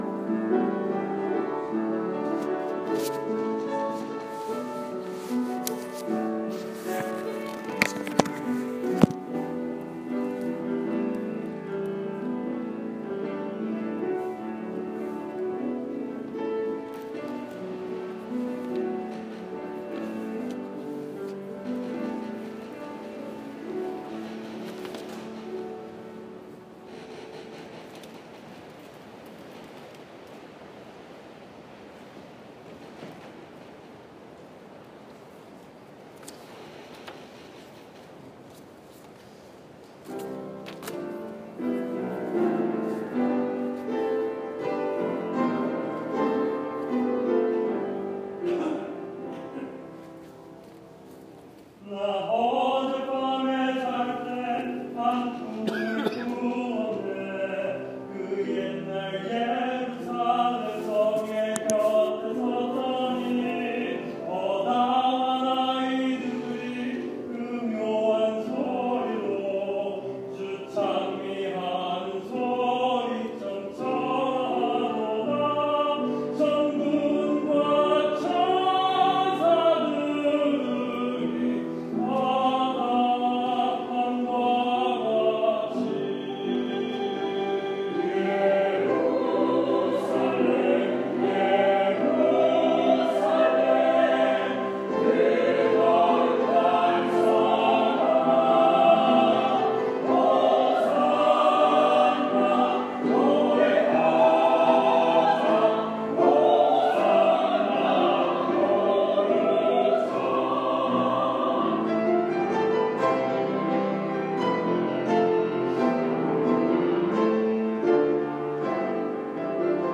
2016년 1월 10일 주일 찬양(거룩한 성, 아담스곡)